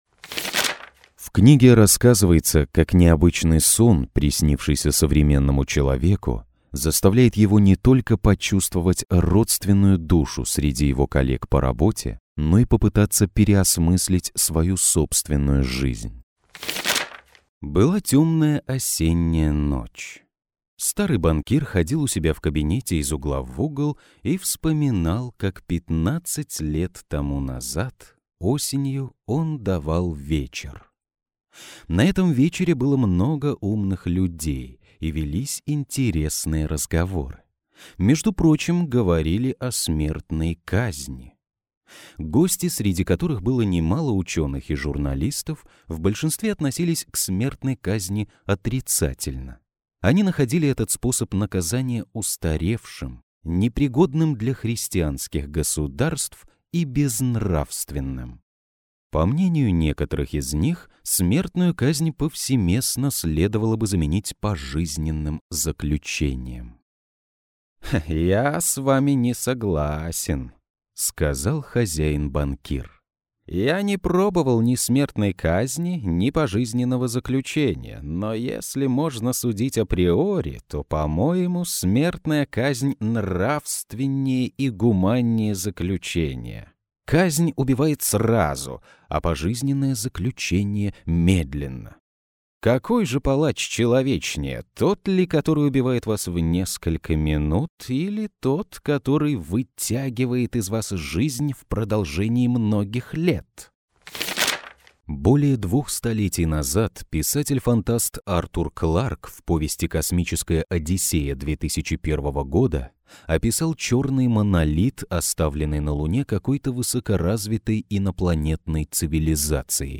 Пример звучания голоса
Муж, Аудиокнига/Средний
Акустическая кабина, микрофон, карта Focusrite - 3rd Gen.